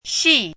chinese-voice - 汉字语音库
xi4.mp3